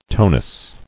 [to·nus]